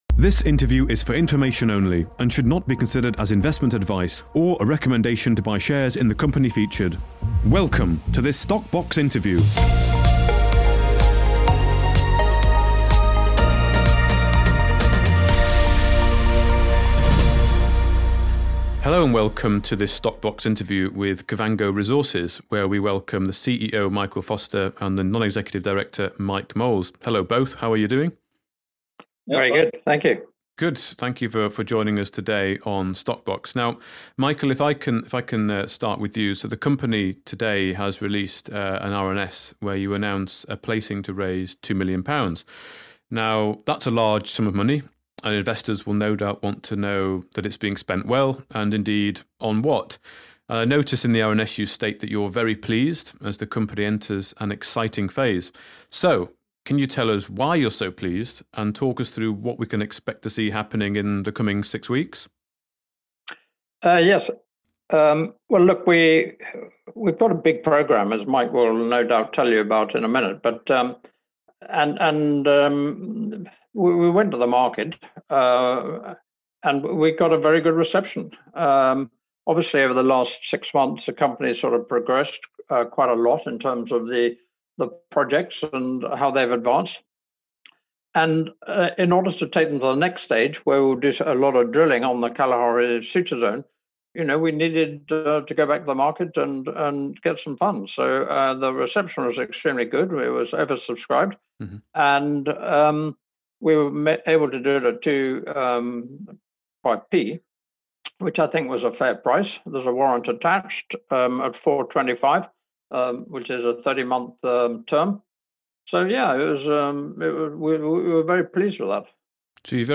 INTERVIEW - Kavango Resources Next Phase